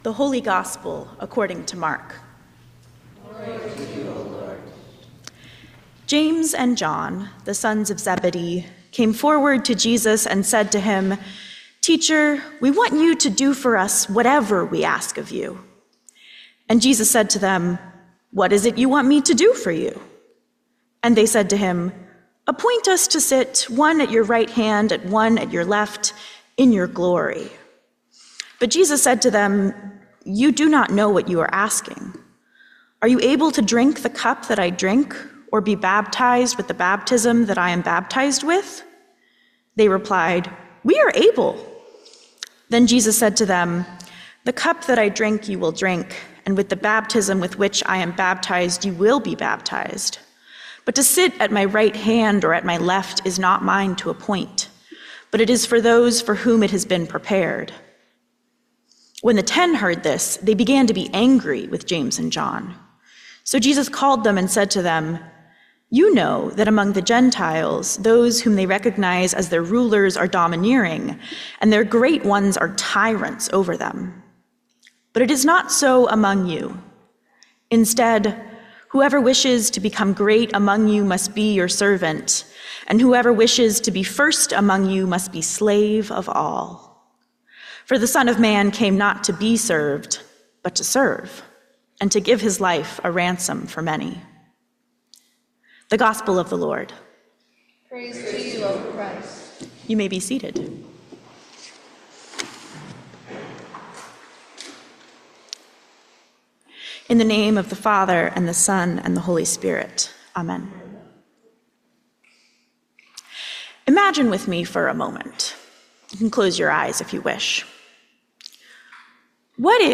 Sermon for the Twenty-Second Sunday after Pentecost 2024